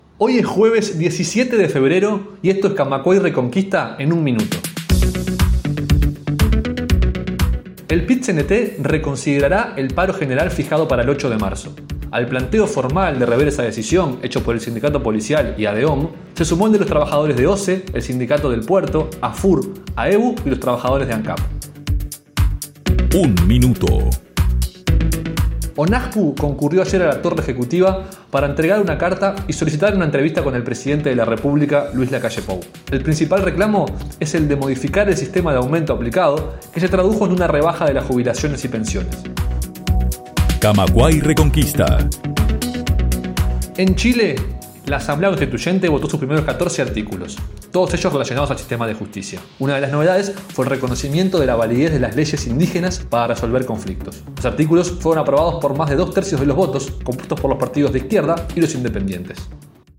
Las noticias del día en 1'